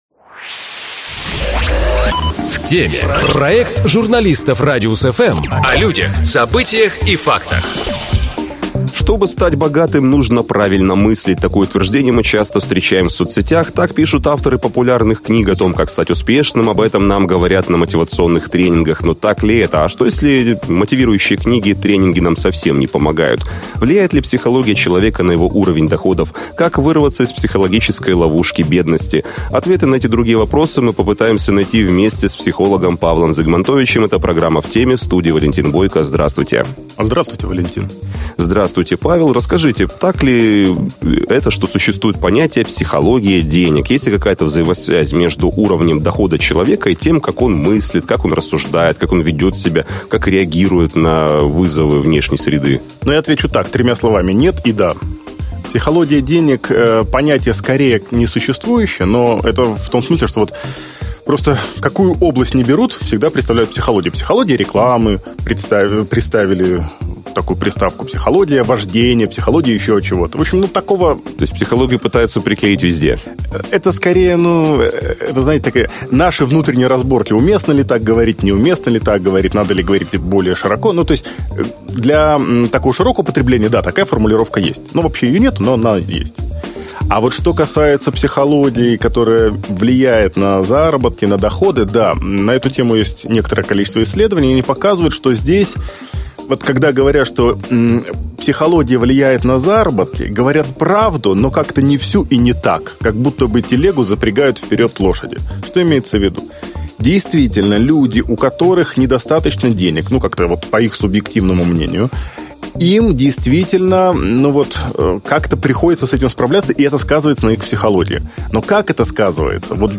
Об этом и не только мы говорим с психологом